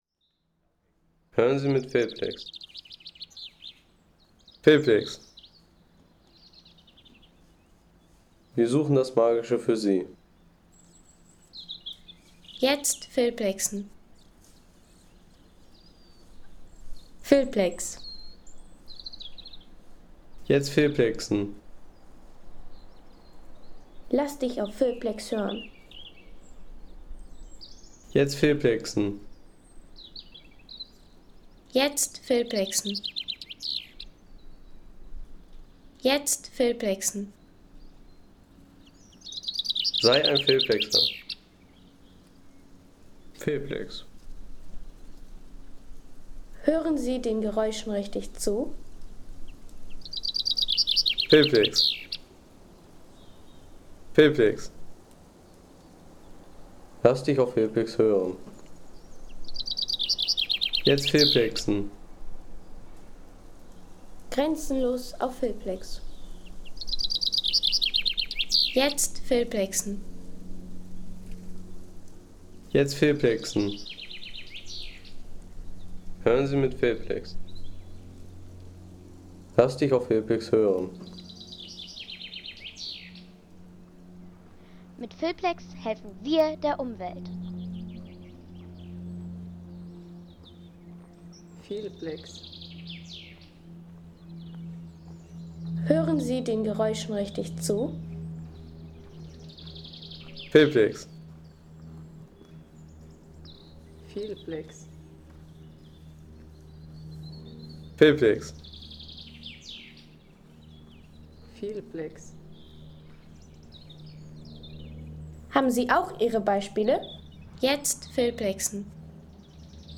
Naturbadesee - Guckaisee
Idyllische Naturbadeseen im Naturpark Rhön.